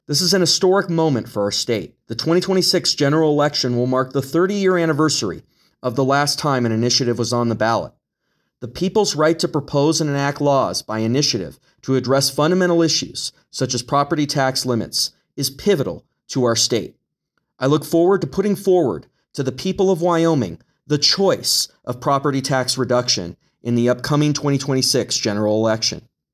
Wyoming Secretary of State Chuck Gray